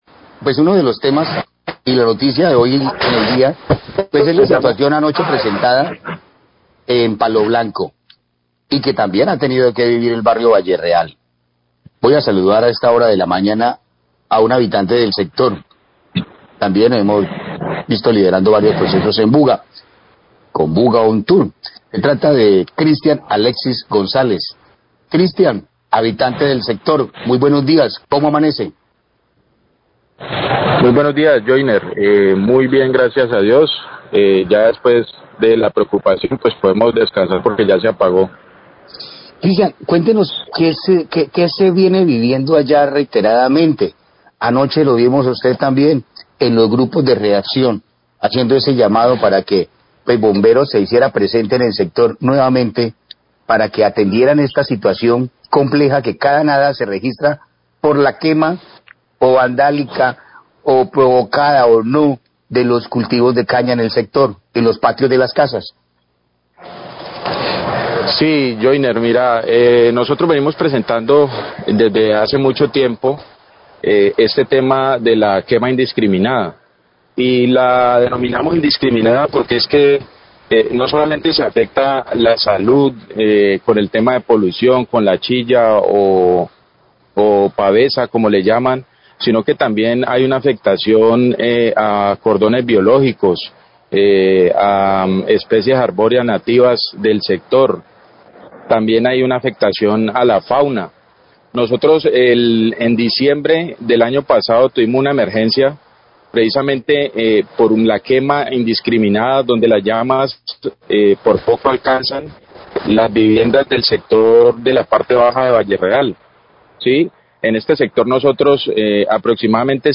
entrevista con habitante de sector de Buga denunciando las quemas de caña y la pavesa